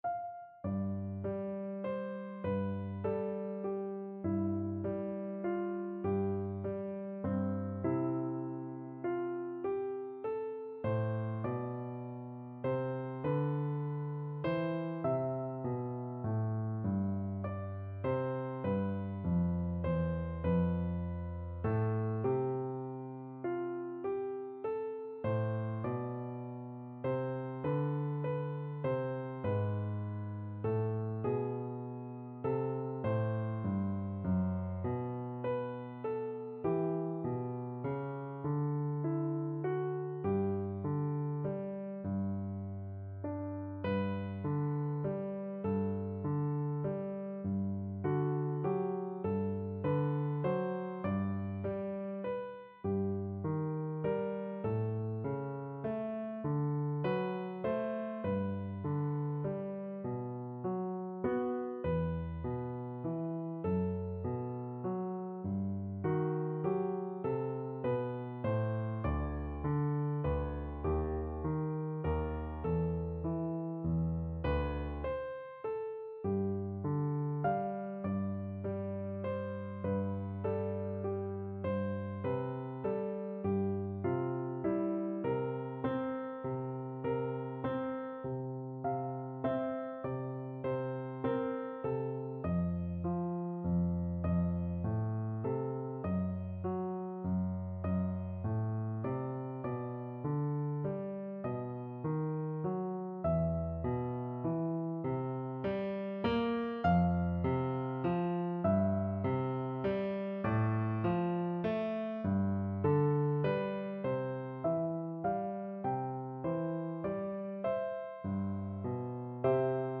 No parts available for this pieces as it is for solo piano.
C major (Sounding Pitch) (View more C major Music for Piano )
MŠ§ig bewegt
3/4 (View more 3/4 Music)
Piano  (View more Easy Piano Music)
Classical (View more Classical Piano Music)